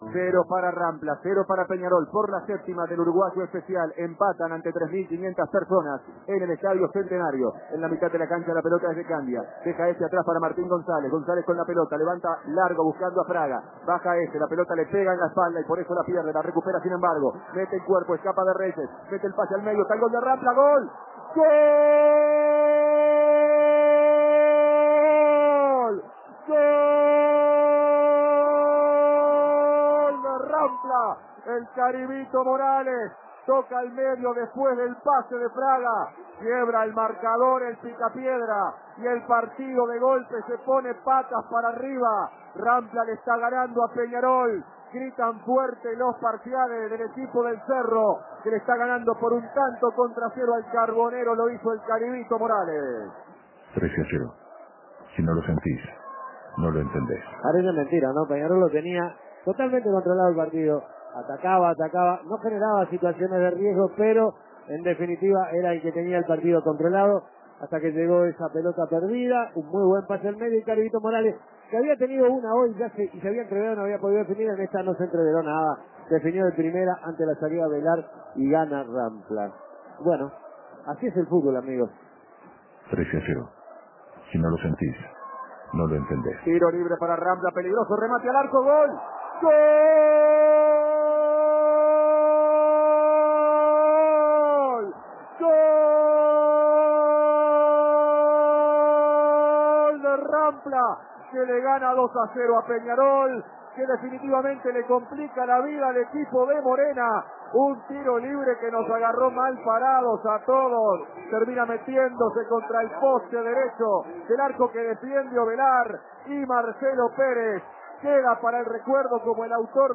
Escuche el relato de los goles del partido Peñarol - Rampla